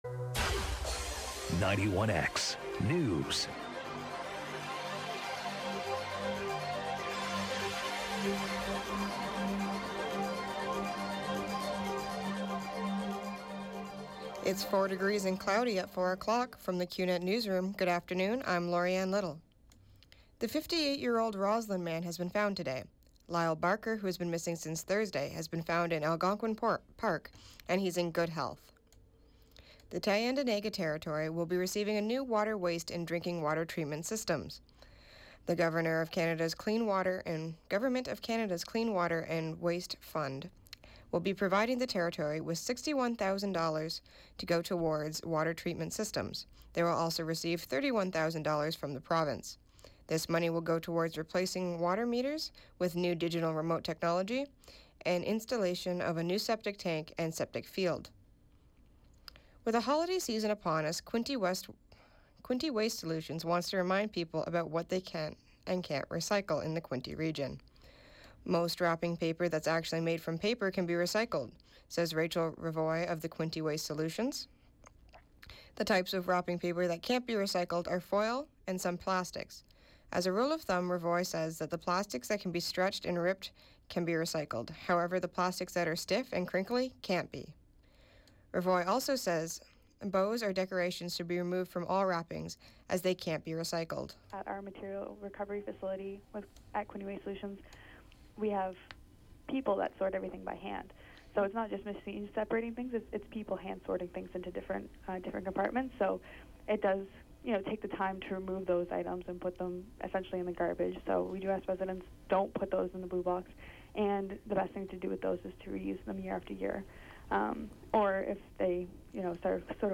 91X Newscast: Tuesday, Dec. 19, 2017. 4p.m.